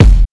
Kick (Kanye).wav